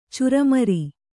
♪ curamari